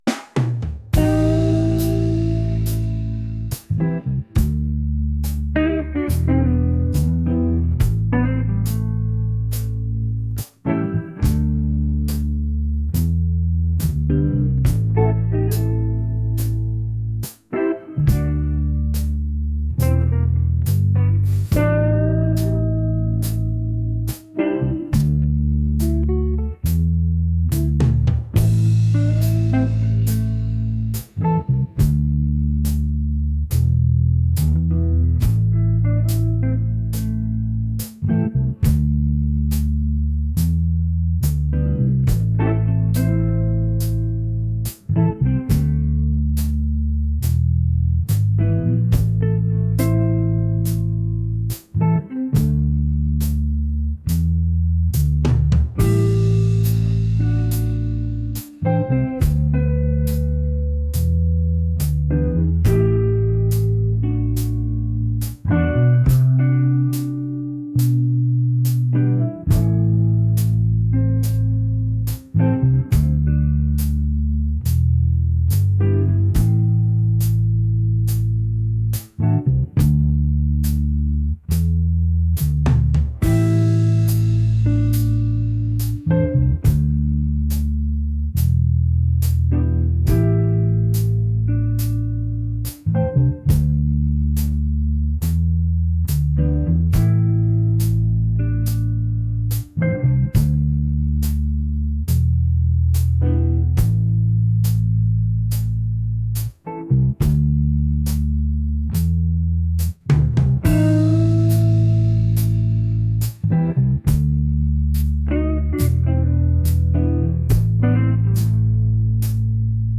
soulful | laid-back